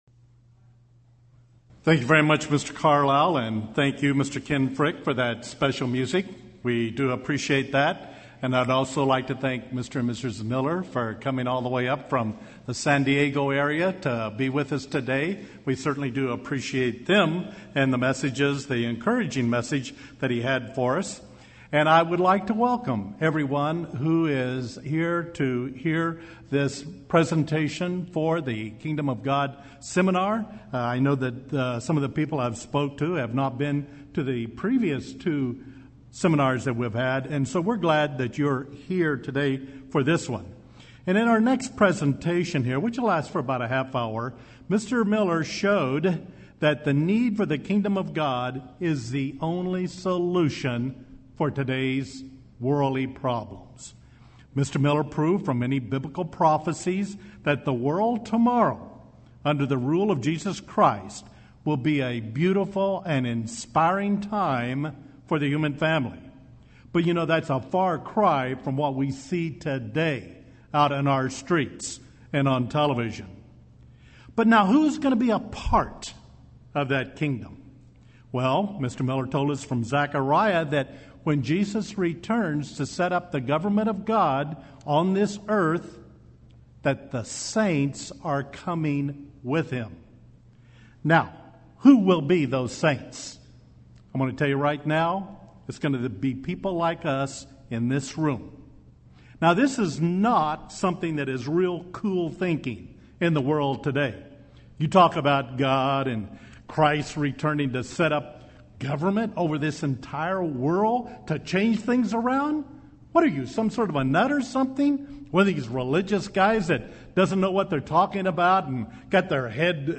Who and when do we enter God’s Kingdom? Is the kingdom in your heart or somewhere else? Learn more in this Kingdom of God seminar.